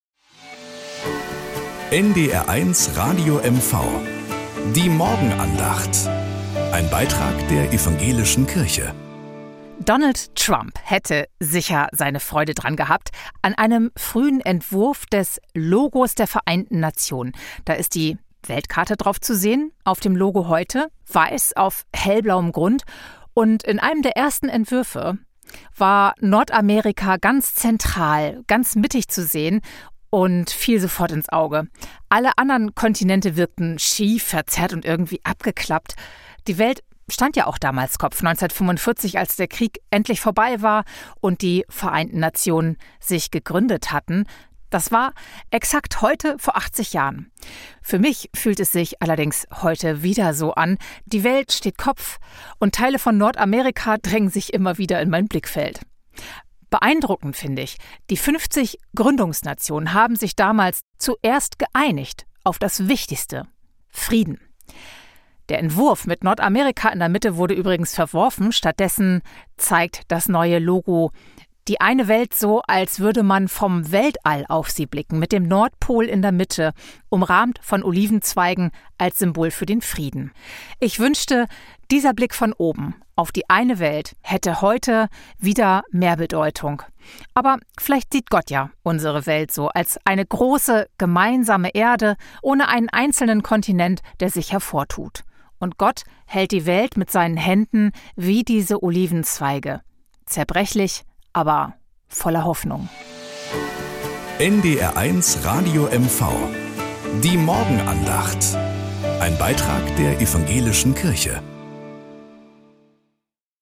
Morgenandacht bei NDR 1 Radio MV
Um 6:20 Uhr gibt es in der Sendung "Der Frühstücksclub" eine Morgenandacht. Evangelische und katholische Kirche wechseln sich dabei ab.